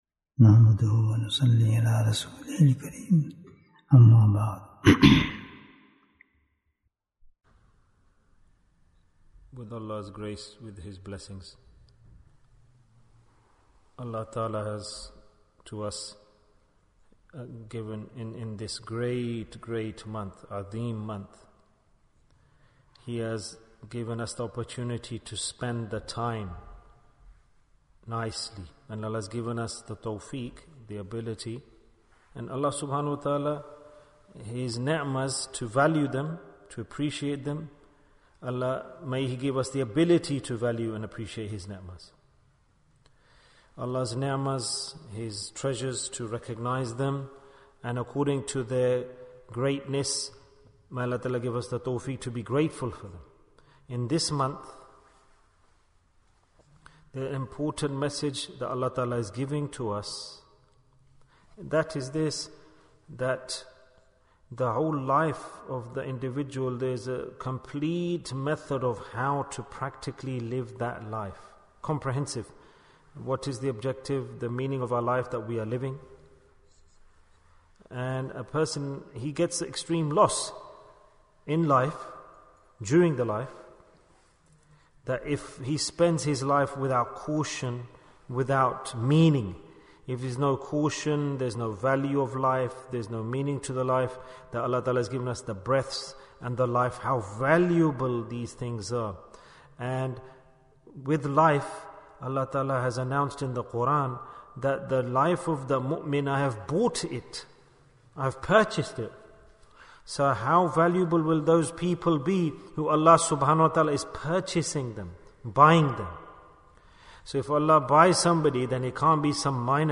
Bayan, 56 minutes10th April, 2023